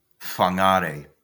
Whangārei (Māori: [ɸaŋaːˈɾɛi]